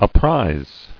[ap·prize]